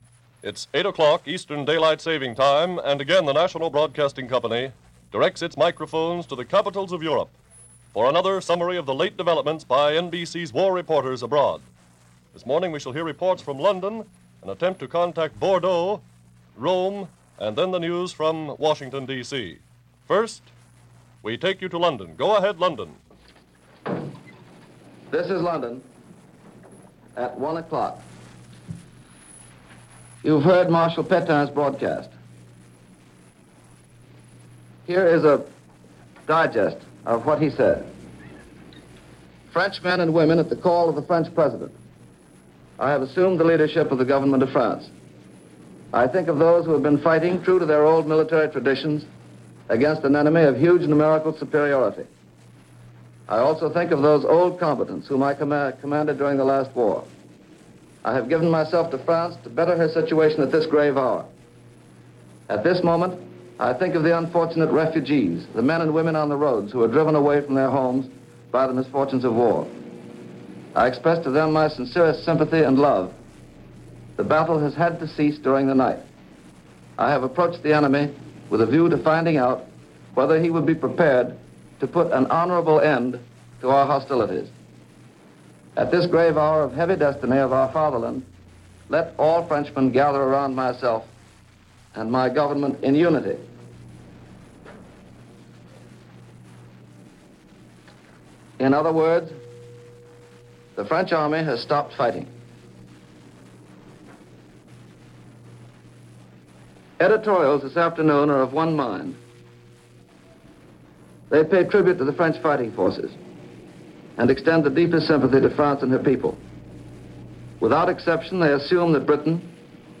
In an emotional address to the world, Marshall Pétain announced that France had ceased fighting and that a negotiate surrender was anticipated over the coming hours.
June 17, 1940 -News Reports – H.V. Kaltenborn commentary – NBC Radio – Gordon Skene Sound Collection